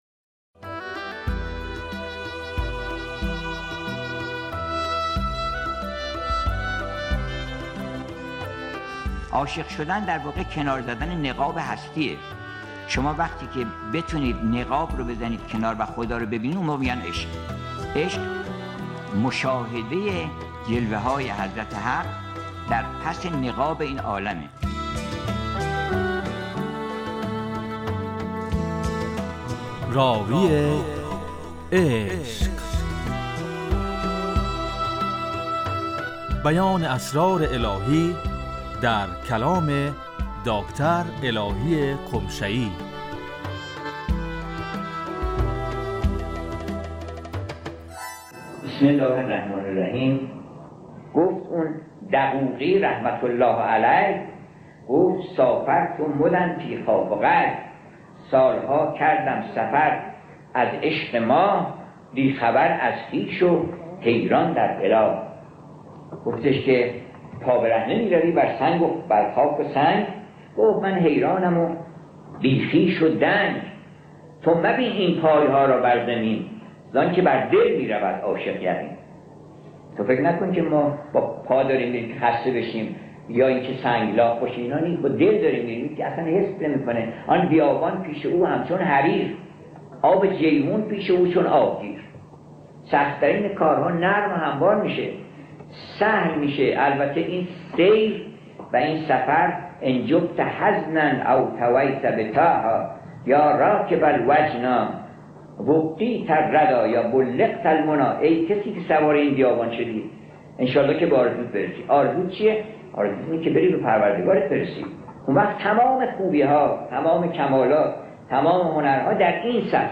راوی عشق - بیان اسرار الهی در کلام دکتر الهی قمشه ای